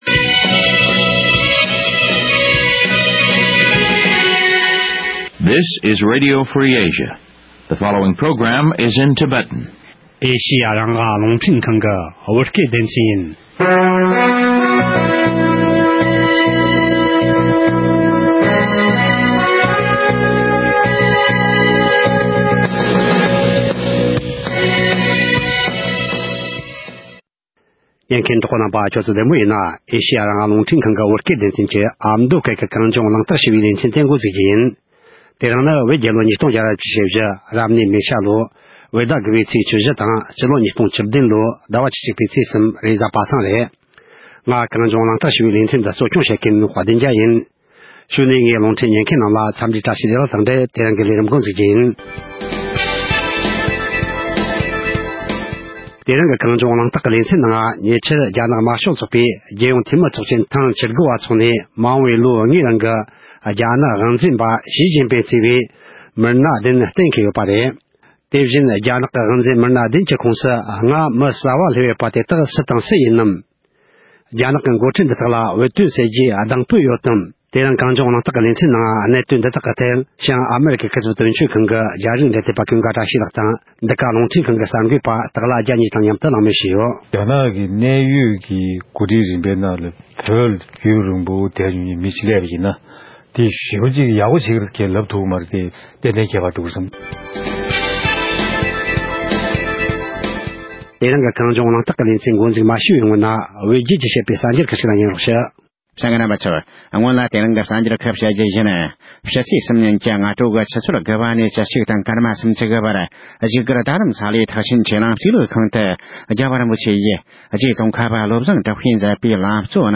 གནད་དོན་འདི་དག་གི་ཐད་གླེང་མོལ་ཞུ་རྒྱུ་ཡིན།
རྣམ་པ་གཉིས་ཕེབས་ཡོད།